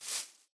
grass5.ogg